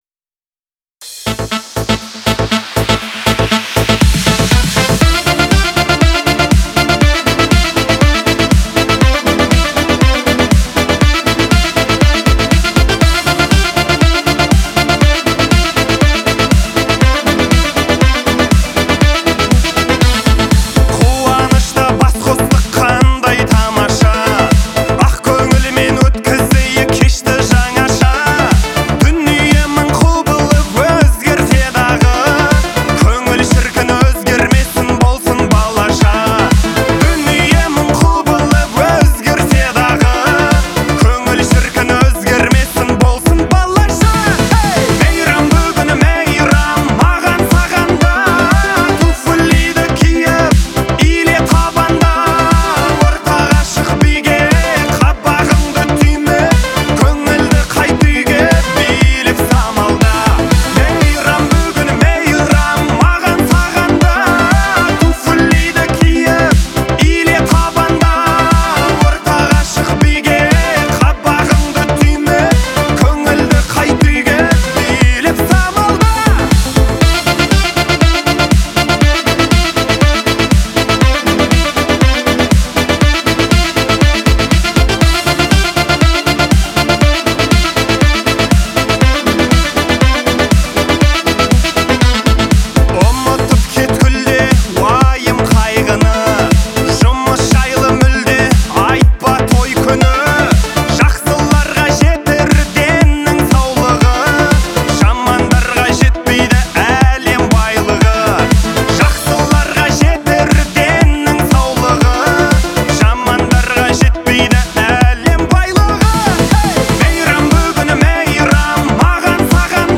это яркая и жизнерадостная песня